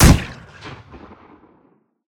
glShootEmpty.ogg